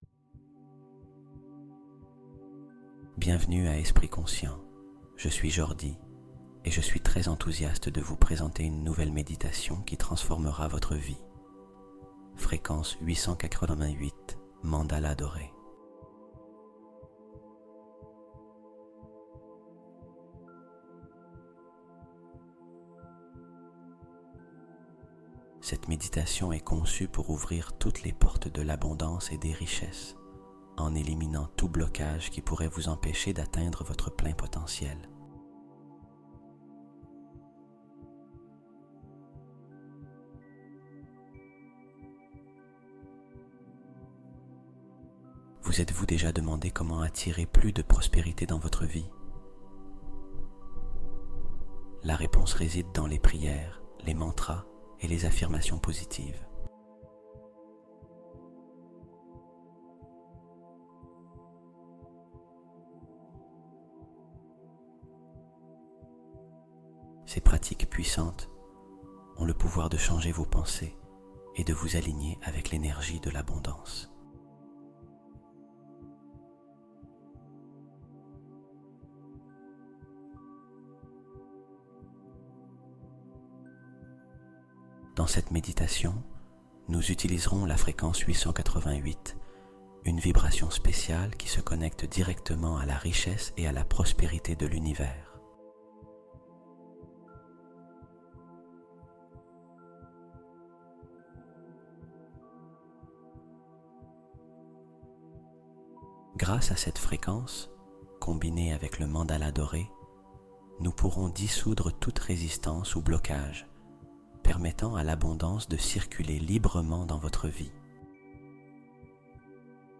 888 Hz Mandala Sacré : Ouvre Les Portes Dorées de l'Abondance et Détruis Tes Blocages
Méditation Guidée